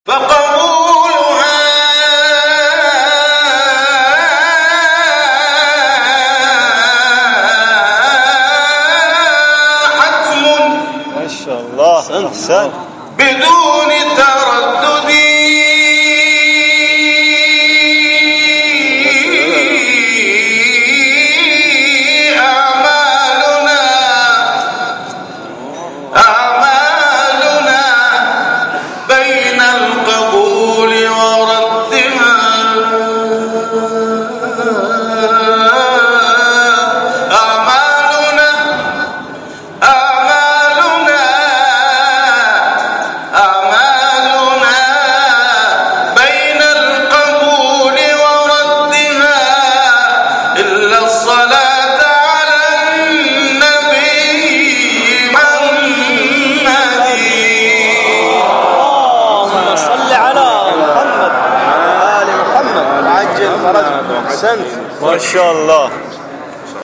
در حسینیه نجفیها، با حضور قاریان مهمان ساوجی برگزار شد.
تلاوت